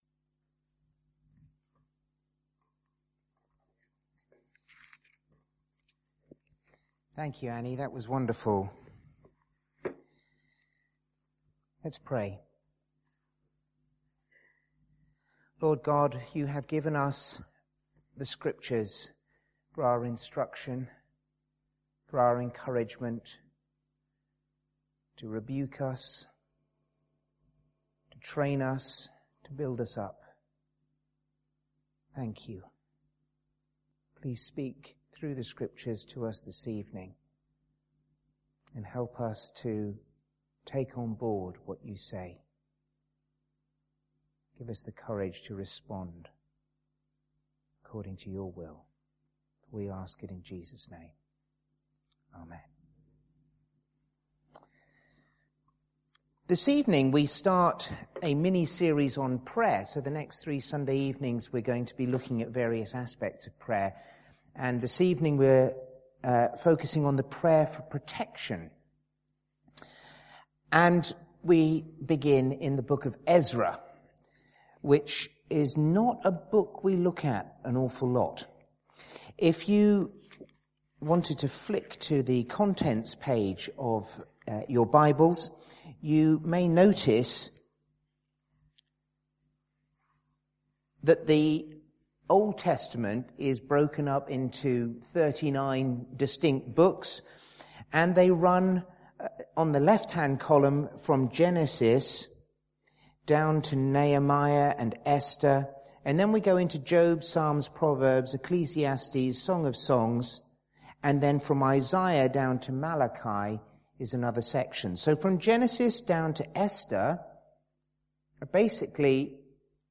Sermons on a Theme
St Paul’s Church, Jersey – Sunday 9 June 2013, 6:30pm – Ezra 8:15-32 & Ephesians 6:10-20